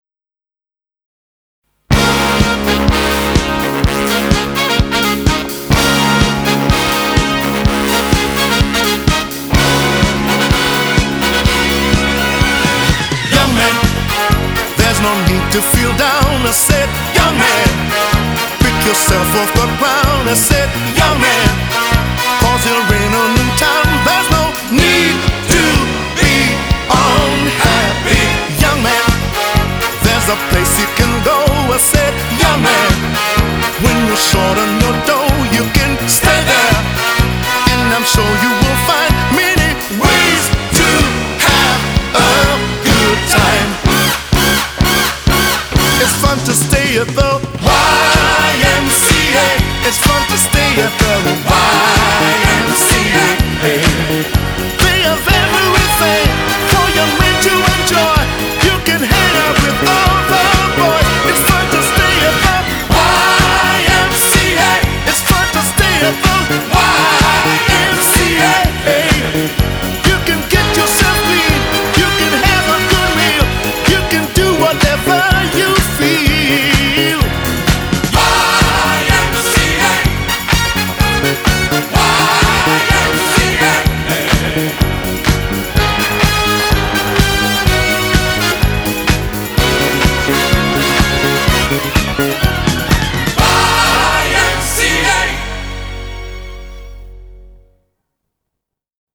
BPM130